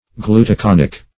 Search Result for " glutaconic" : The Collaborative International Dictionary of English v.0.48: Glutaconic \Glu`ta*con"ic\, a. [Glutaric + aconitic.]